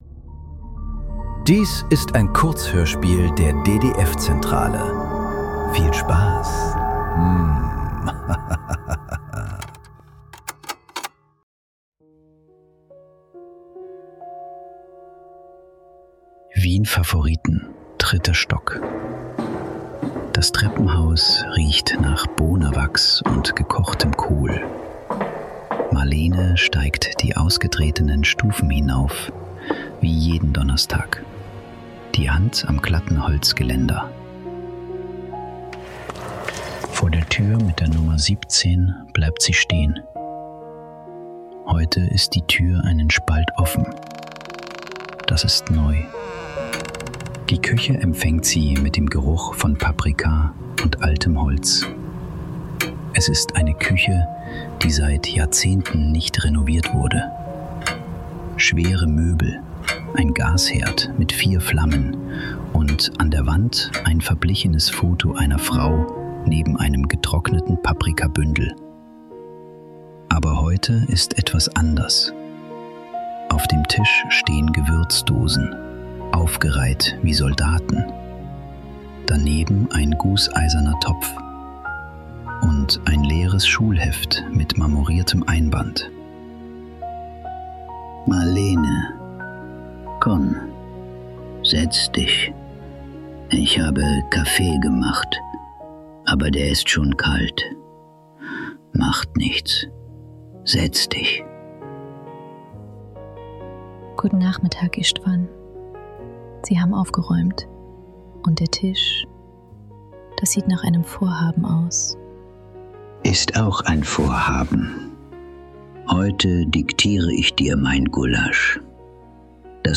Die letzte Zutat ~ Nachklang. Kurzhörspiele.